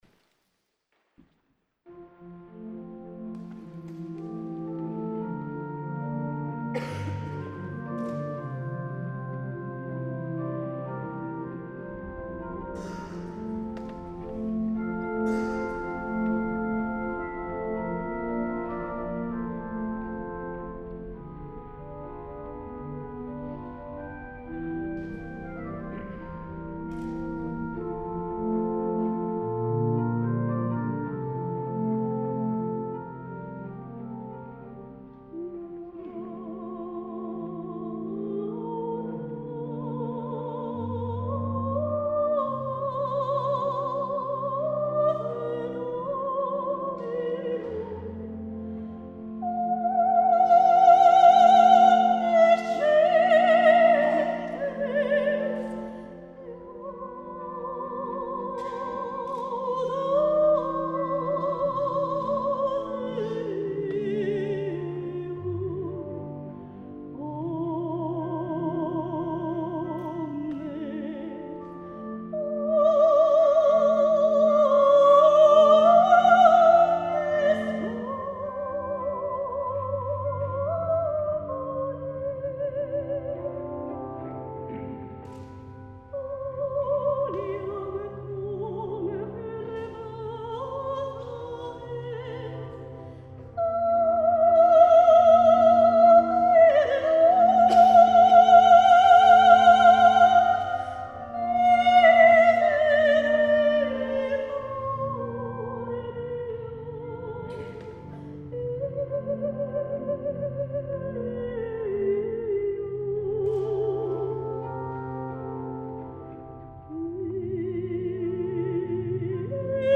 La Corale San Gaudenzio di Gambolo' (Church Choir)2015
Concerti di Natale nella Chiesa Parrocchiale
Registrazione audio MP3 di alcuni brani del concerto